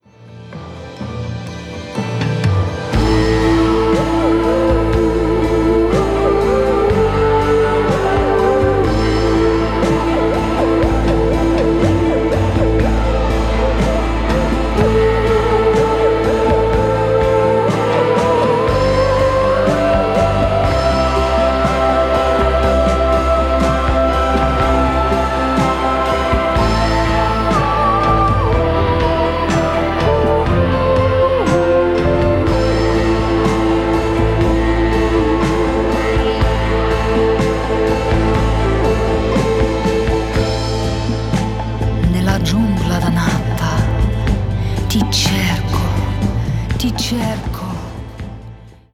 Filmmusiker
Sängerin
Filmmusik